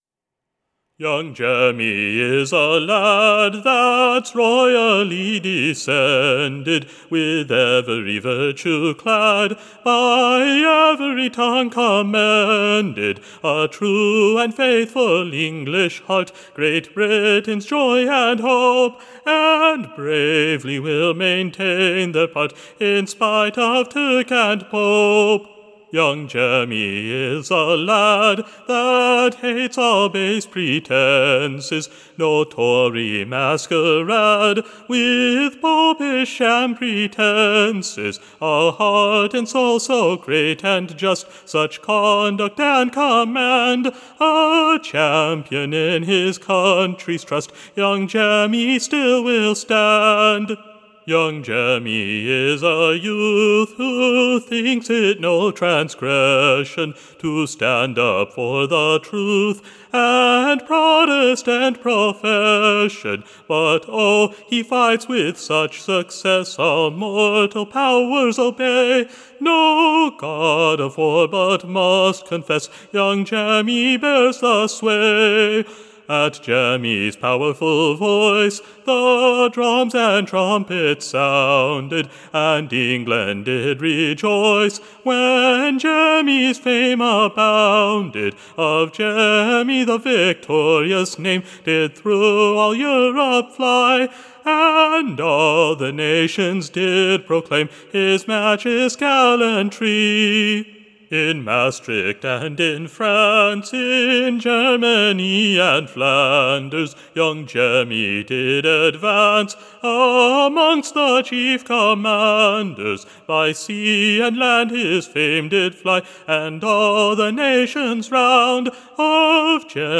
/ An Excellent New Ballad, Tune Imprint To an Excellent New Tune. Standard Tune Title Young Jemmy Media Listen 00 : 00 | 10 : 20 Download H135861.mp3 (Right click, Save As)